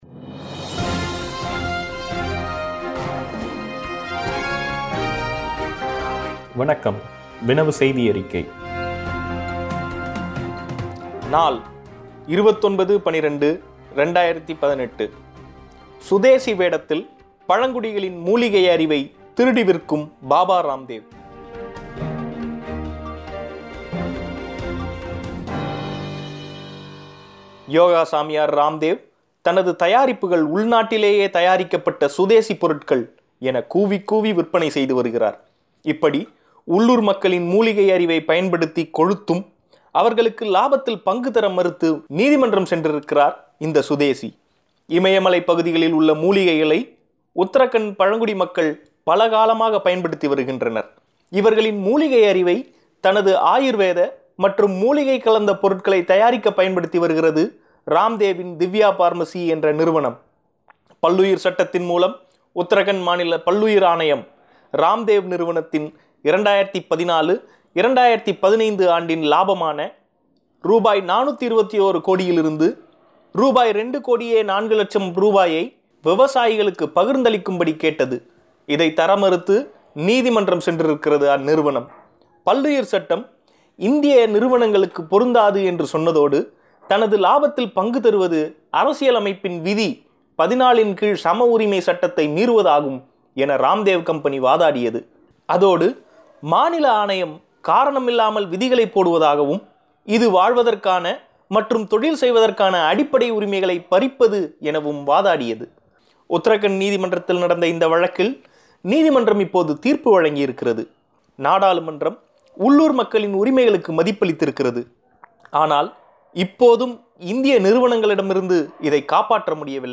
ஓட்டுக்குப் பணம் வாங்குவதால் தமிழகம் முழுவதும் தேர்தலைப் புறக்கணிக்கலாமா ? கேள்வி பதில் ... கஜா புயல் பாதித்த பகுதிகளைத் தாக்கும் நுண்கடன் நிறுவனங்கள் ! ... உள்ளிட்ட கட்டுரைகள் ஒலி வடிவில்.